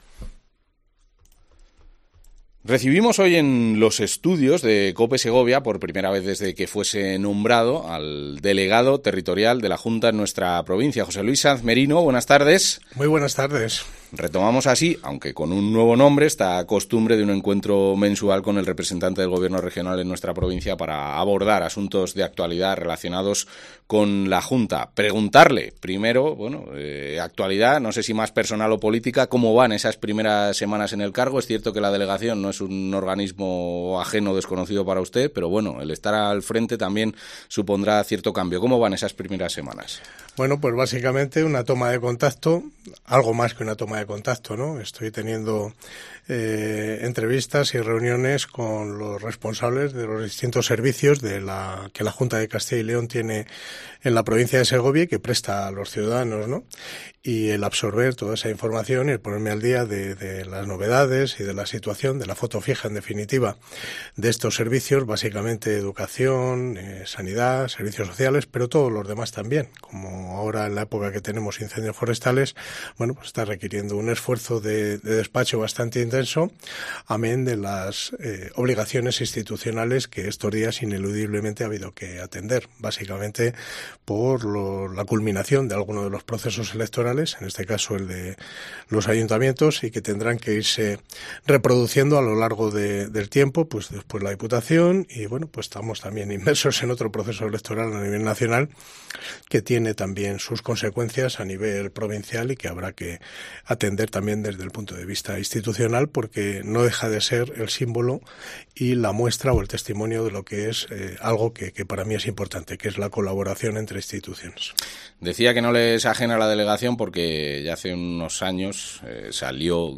Es su primera entrevista con COPE Segovia desde que tomase posesión de ese cargo